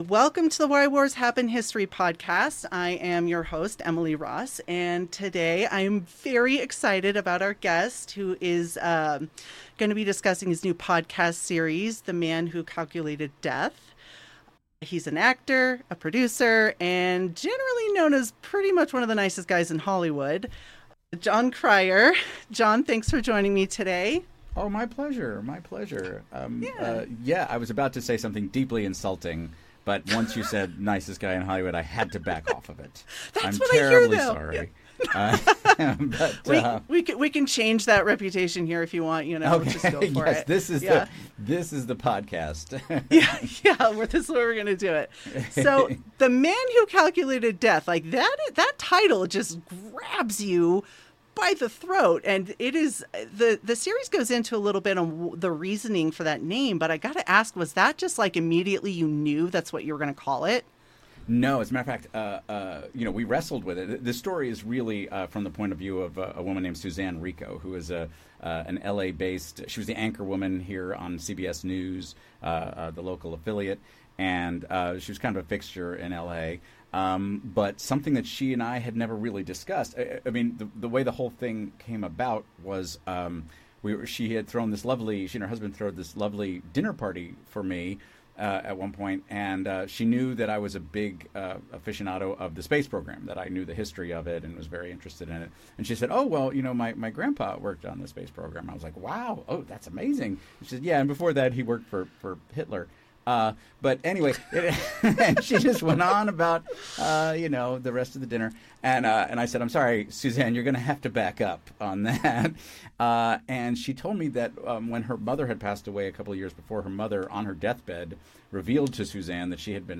Why Wars Happened Interview History Podcast